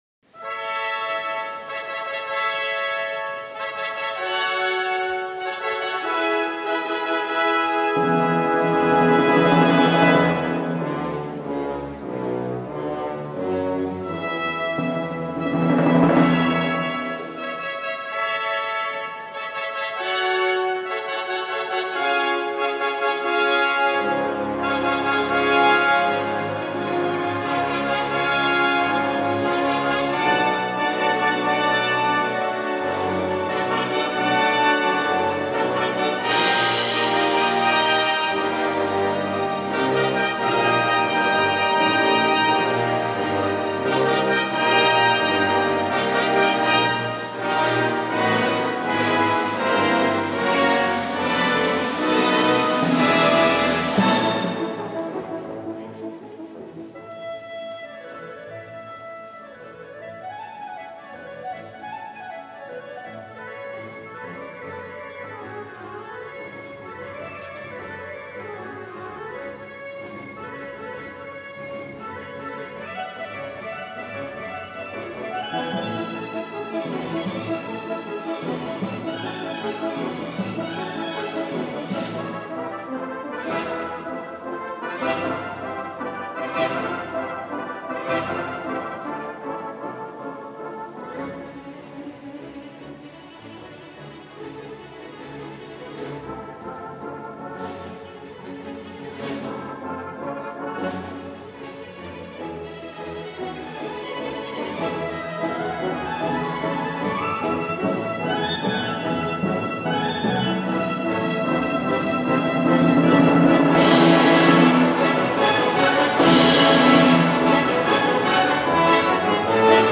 by Dmitri Shostakovich | Colorado Symphony Orchestra
Festive-Overture-Gala-Festival.mp3